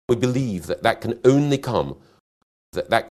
Conjunction that is usually weak, containing the colourless vowel schwa, /ə/, which is not shown in spelling.
To illustrate the distinction between the two kinds of that, here are real utterances in which native speakers say the conjunction and the demonstrative in sequence, /ðət ðat/: